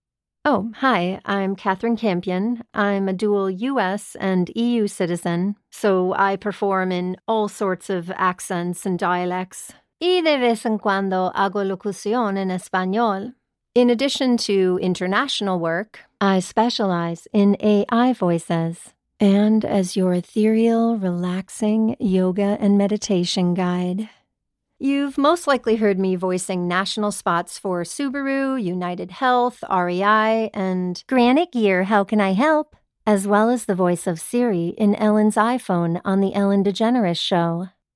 Female
American English (Native) , British English , Canadian English , Irish , Latin American Spanish
Authoritative, Cheeky, Confident, Cool, Corporate, Engaging, Friendly, Natural, Posh, Reassuring, Smooth, Warm, Witty, Versatile, Young, Approachable, Assured, Bright, Character, Conversational, Energetic, Funny, Gravitas, Soft, Upbeat
Voice reels
commercial.mp3
Microphone: AKG c414; Synco D2